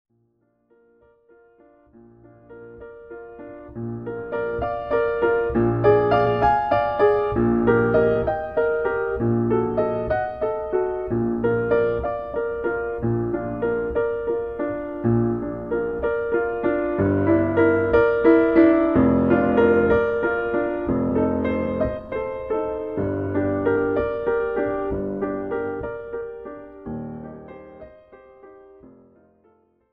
This is an instrumental backing track cover.
• Without Backing Vocals
• No Fade